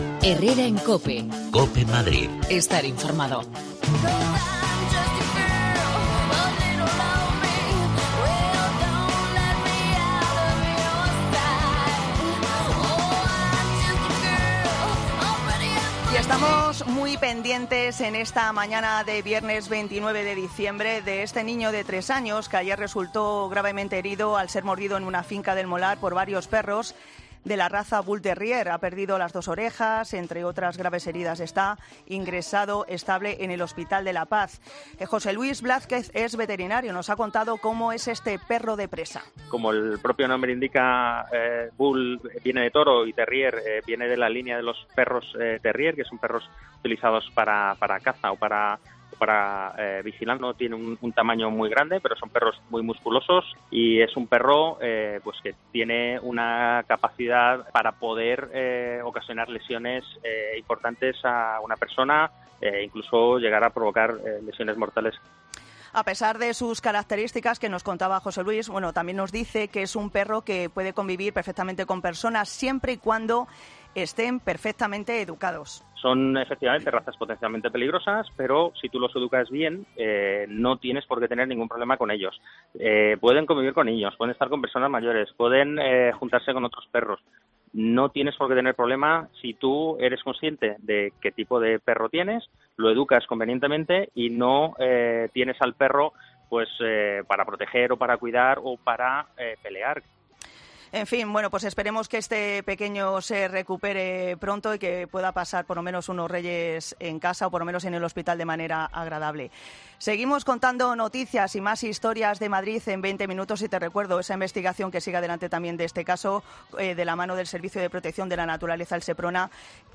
El veterinario